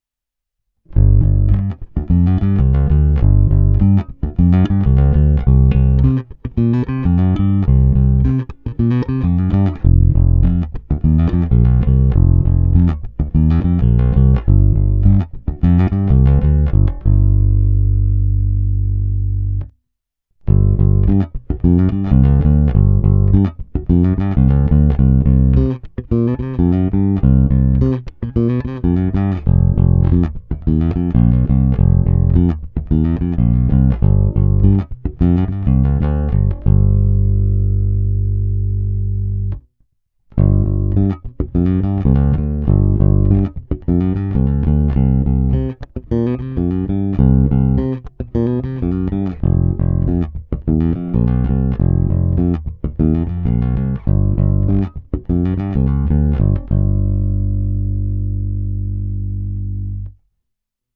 Neskutečně pevný, zvonivý, s těmi správnými středy, co tmelí kapelní zvuk, ale při kterých se basa i prosadí.
Není-li uvedeno jinak, následující nahrávky jsou provedeny rovnou do zvukové karty, jen normalizovány, jinak ponechány bez úprav.